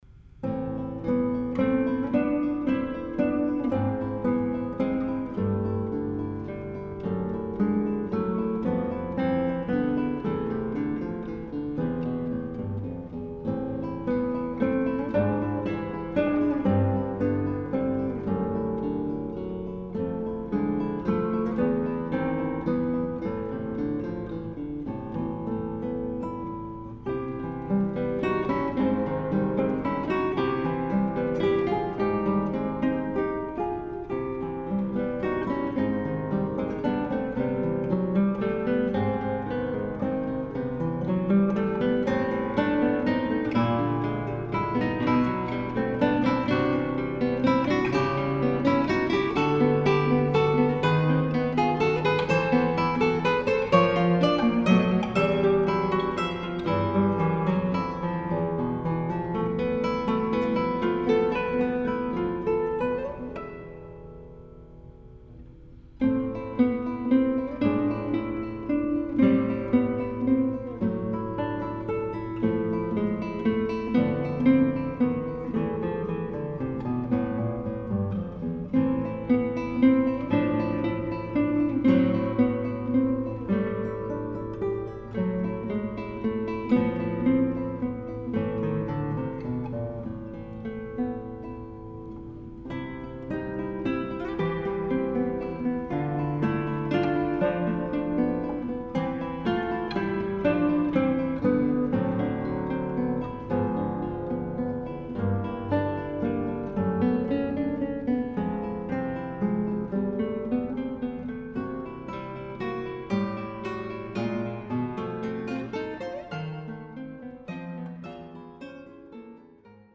Guitar
tongue-in-cheek musical parodies